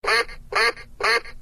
7a_quack.ogg